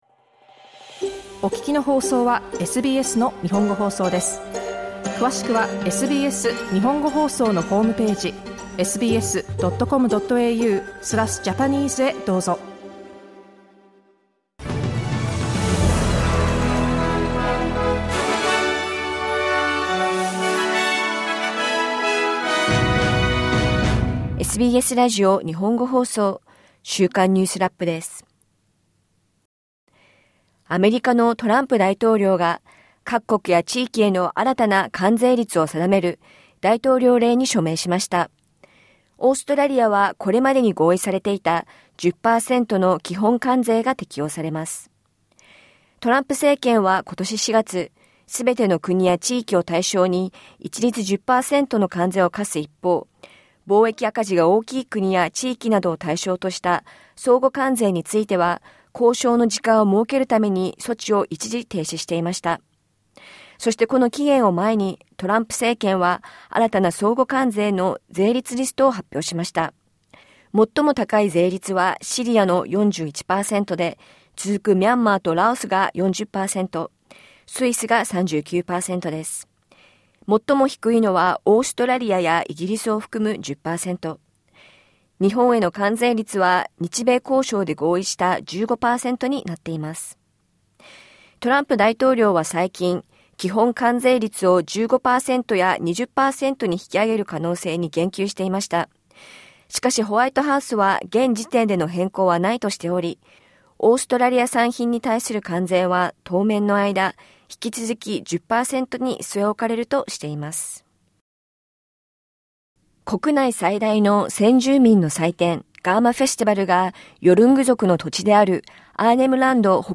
国内最大の先住民の祭典、ガーマ・フェスティバルがヨルング族の土地であるアーネム・ランド北東部の遠隔地で始まりました。ガザにおける食糧不足が深刻化するなか、オーストラリア政府の上級閣僚からは、「パレスチナ国家の承認は時間の問題だ」とする声があがっています。1週間を振り返るニュースラップです。